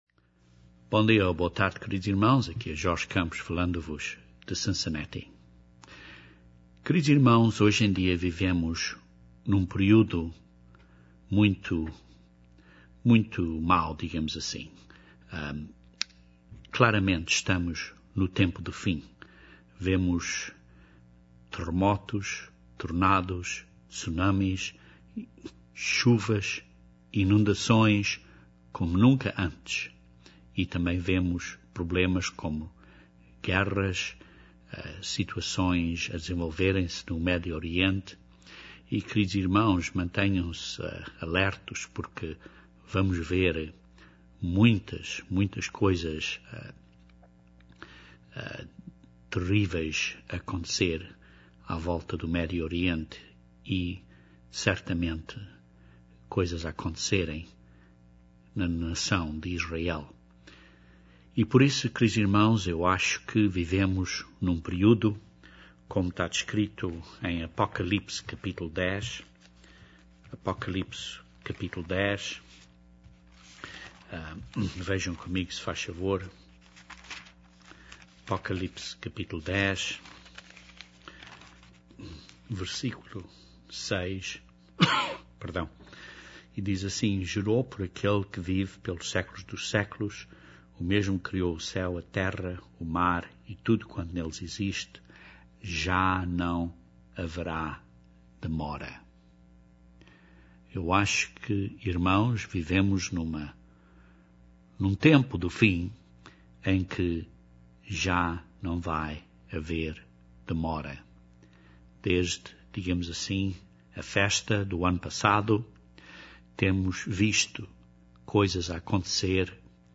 Este sermão demonstra que Deus é o "Senhor de toda a terra", e que ele levanta líderes e os guia pelo Seu Espírito. O Dia de Pentecostes celebra a dávida do Espírito a nós para fazermos a Obra dEle, como membros do Seu Corpo.